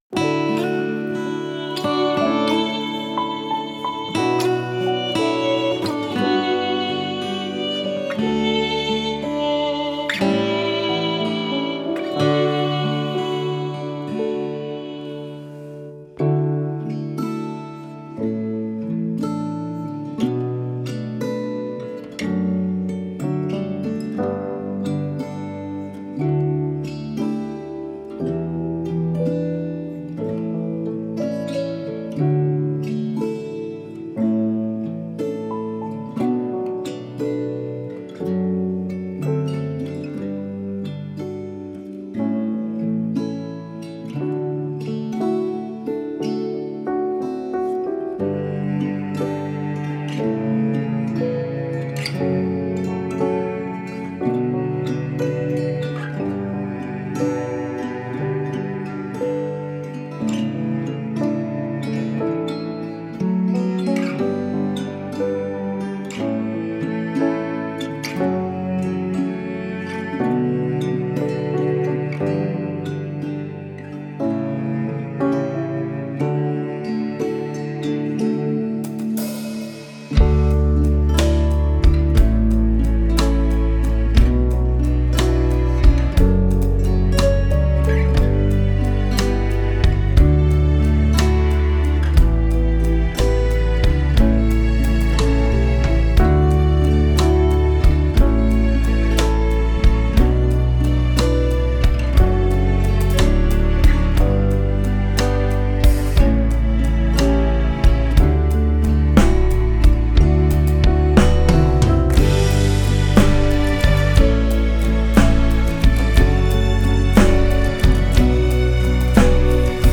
mp3 伴奏音樂